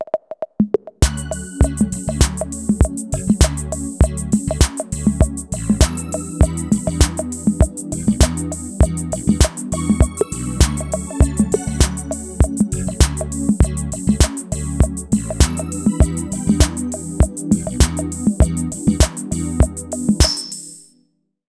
CR78 DRUMS.wav